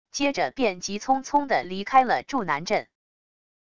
接着便急匆匆的离开了祝南镇wav音频生成系统WAV Audio Player